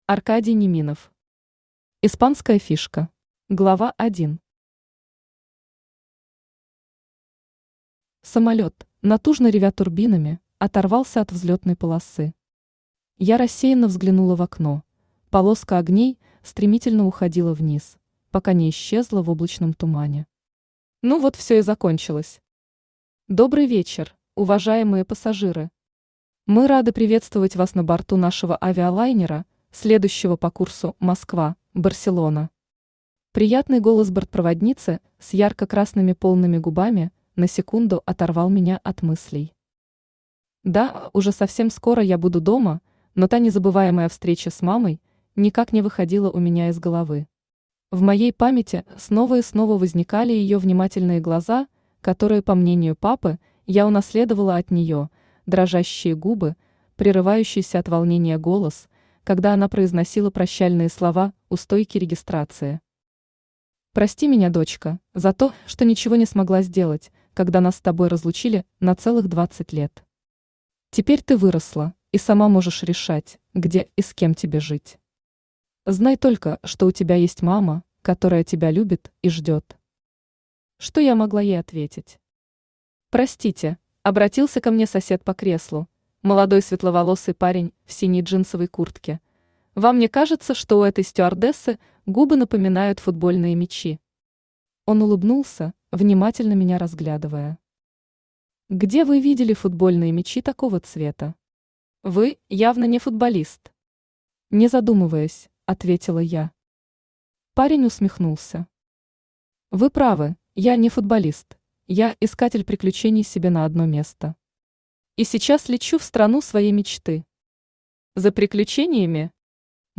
Аудиокнига Испанская фишка | Библиотека аудиокниг
Aудиокнига Испанская фишка Автор Аркадий Неминов Читает аудиокнигу Авточтец ЛитРес.